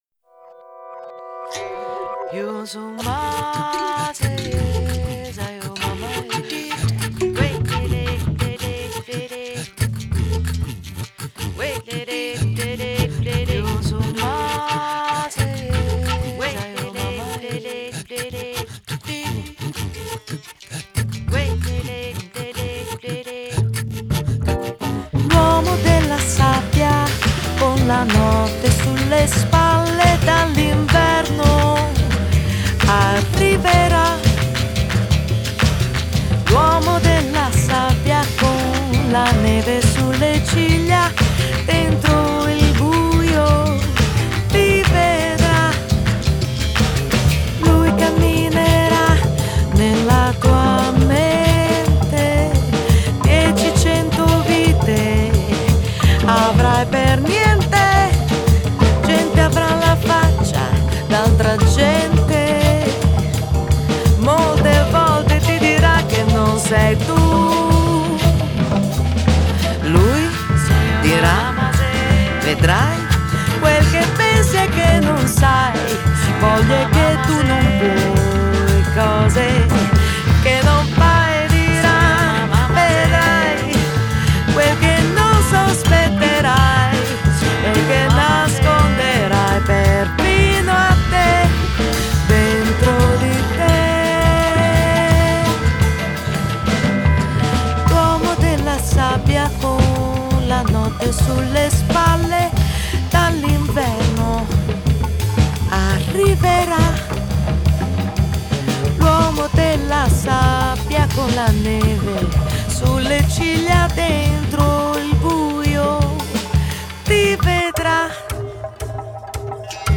Genre: Pop Lounge, Bossa